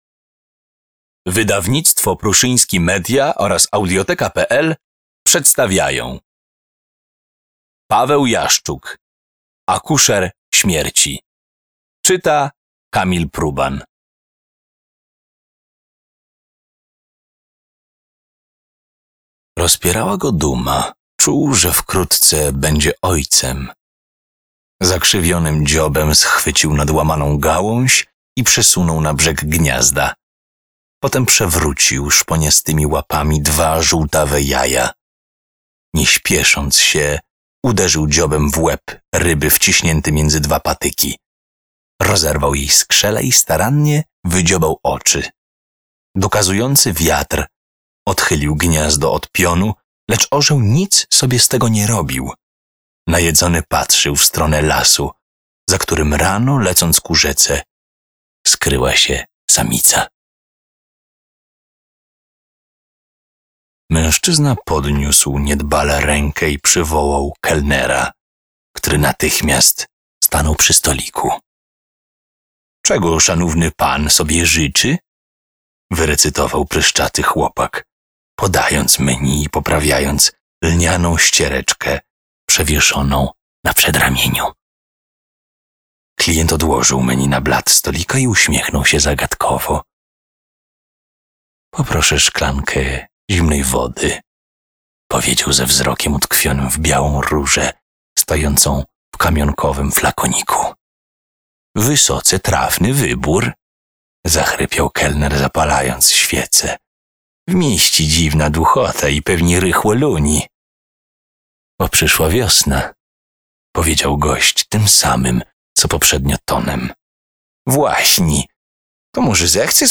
Akuszer śmierci - Paweł Jaszczuk - audiobook